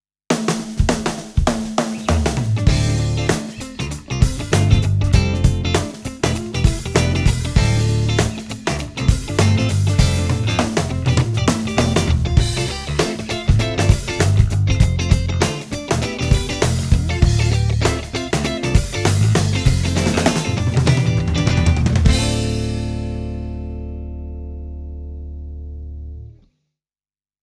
ミックスをLine INに入れてサミングして頂きました。
ビンテージのNeveでもサミングされる方もいるんですけど、タイトな感じに仕上がるというか、音が締まってくる感じになります。
Neve Sample_1073DPX_summing